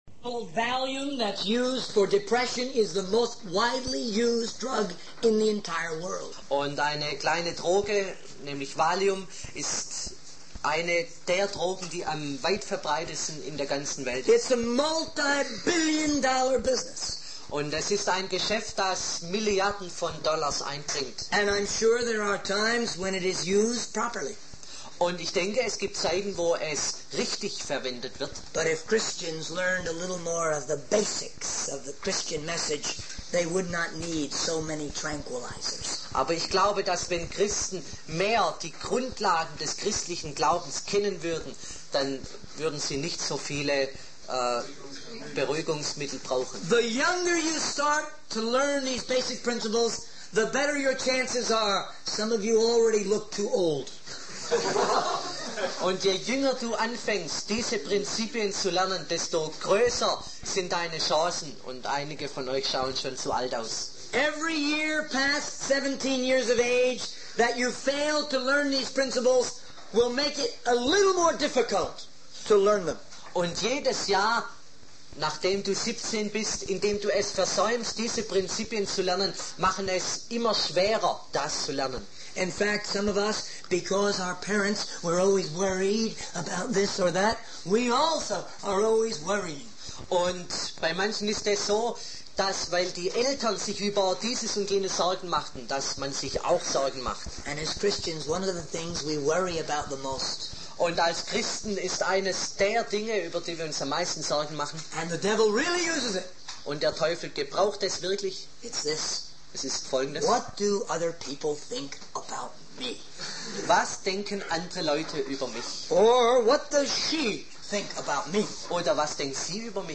In this sermon, the speaker discusses the importance of learning and applying the teachings of God.